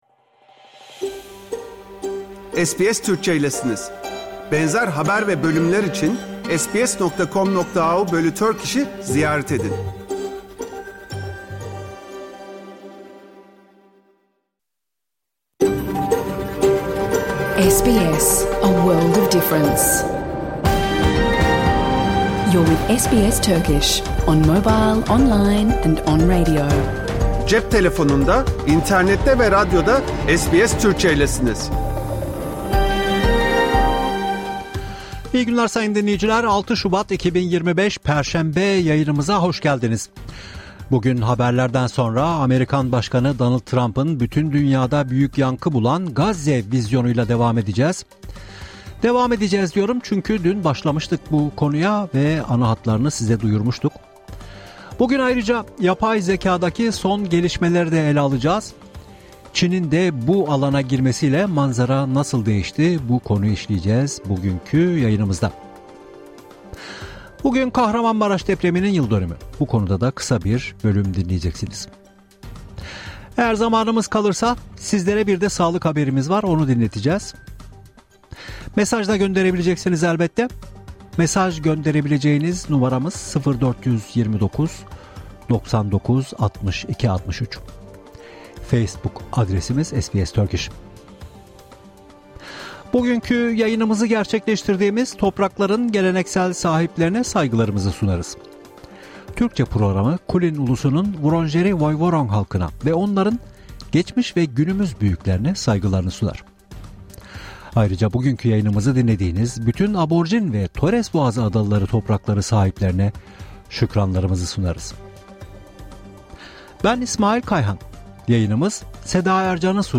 Hafta içi Salı hariç her gün Avustralya doğu kıyıları saati ile 14:00 ile 15:00 arasında yayınlanan SBS Türkçe radyo programını artık reklamsız, müziksiz ve kesintisiz bir şekilde dinleyebilirsiniz.
🎧 Bugünkü Program SBS Türkçe Haber Bülteni 6 Şubat depreminin 2. Yıldönümü Trump'ın Gazze planına tepki: Etnik temizlik Broadmeadows - Campbellfield'deki Ford fabrikası arazisi satıldı Çin yapımı yapay zeka Avustralya devlet sistemlerinden yasaklandı.